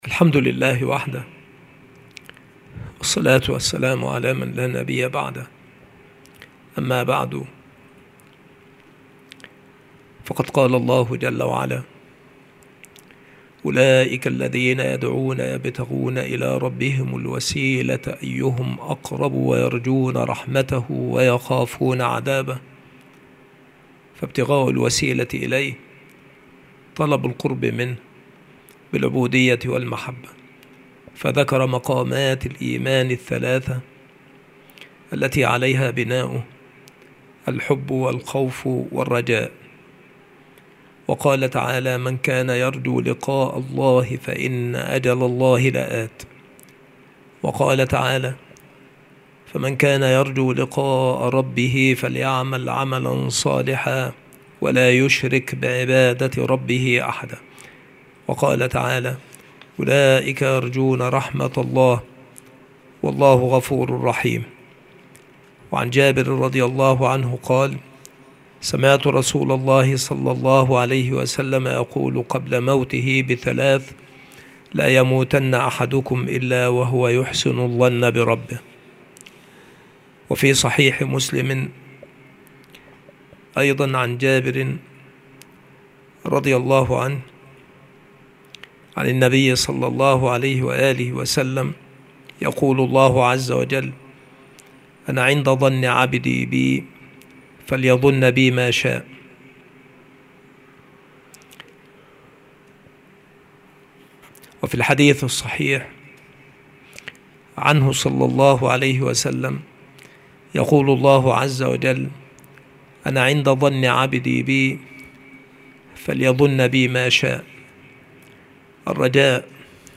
مكان إلقاء هذه المحاضرة المكتبة - سبك الأحد - أشمون - محافظة المنوفية - مصر عناصر المحاضرة : علامة حسن الرجاء. درجات الرجاء. من معاني ( الرجاء ) في الكتاب والسنة. من أقوال العلماء في الرجاء.